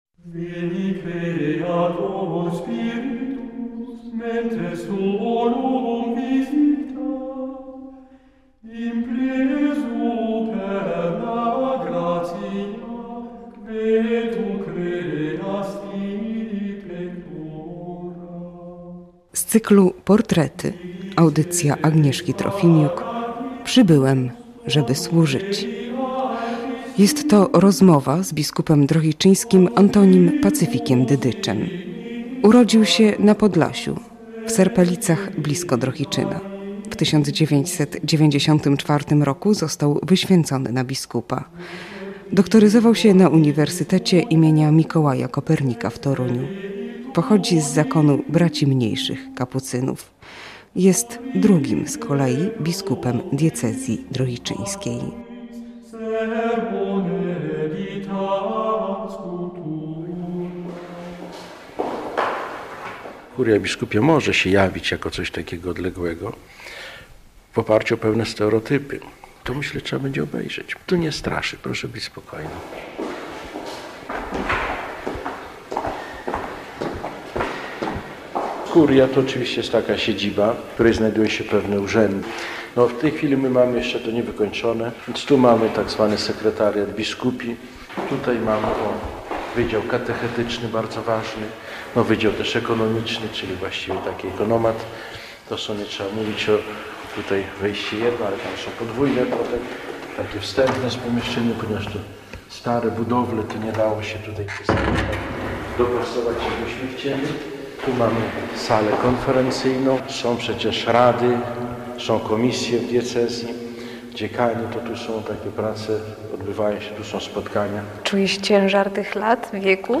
Reportaż
Biskup Antoni Dydycz o sobie w archiwalnym reportażu